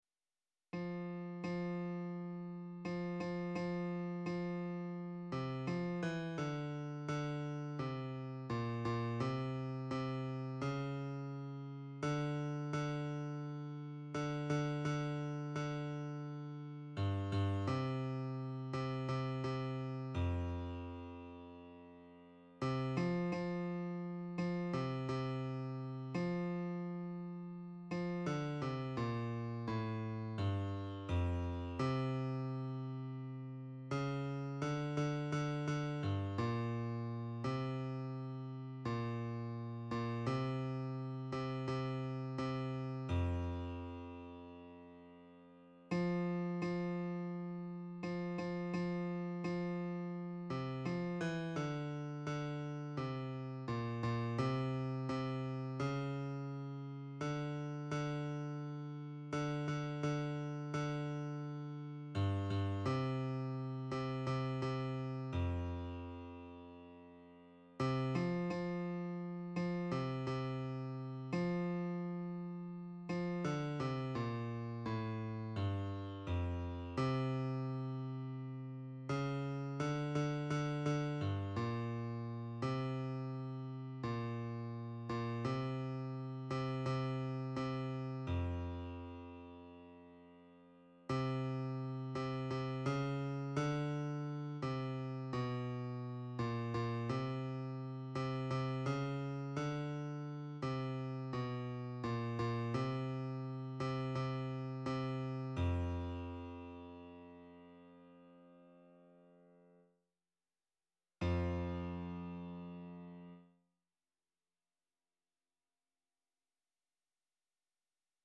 R�p�tition de la pi�ce musicale N� 704
My Lord what a morning_basse.mp3